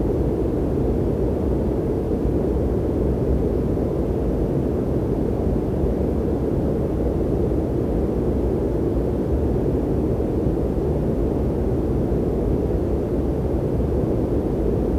H145_Wind_In-right.wav